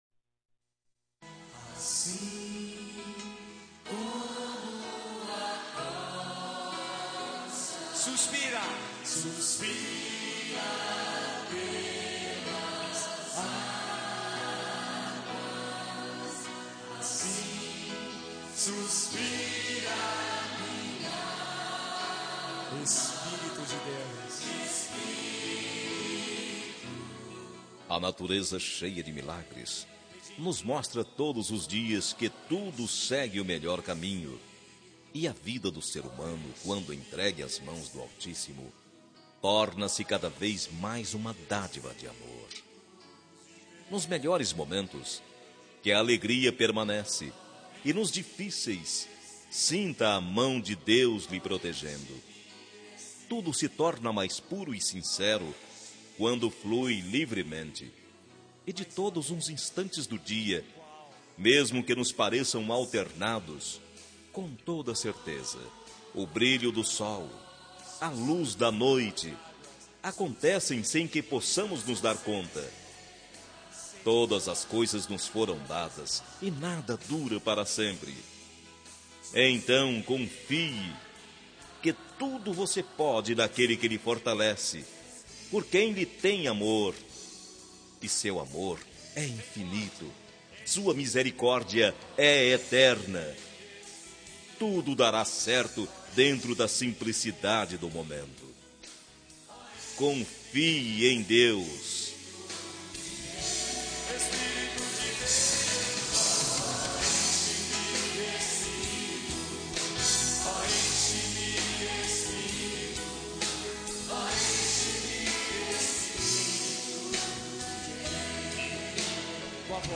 Telemensagem de Otimismo – Voz Masculina – Cód: 4990-4 – Religiosa